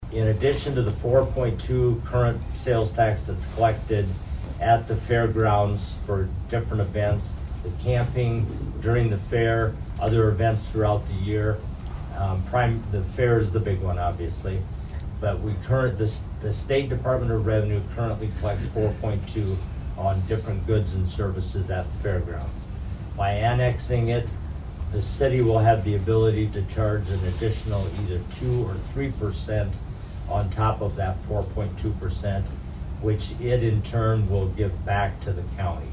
Sutton discuss how annexing this will impact the city and the county’s finances.